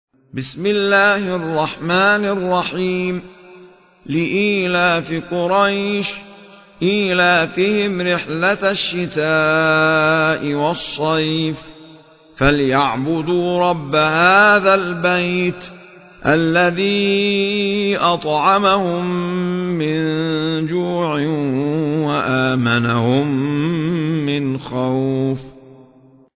المصاحف - أحمد عيسى المعصراوي
المصحف المرتل - ابن وردان عن أبي جعفر